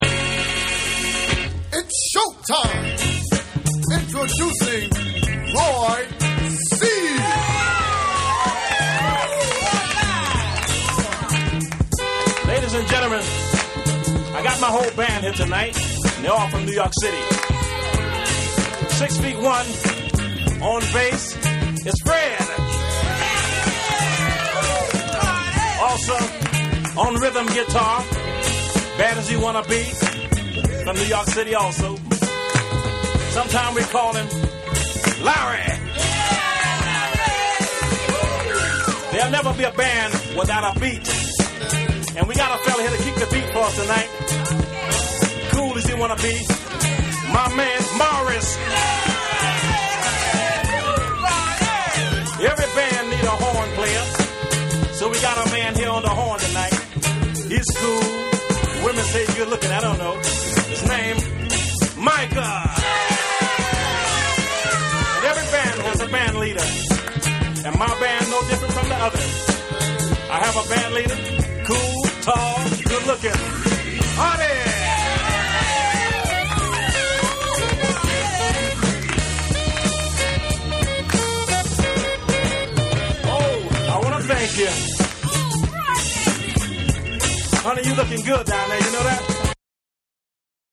全体をとおして完成度の高いファンキー・チューン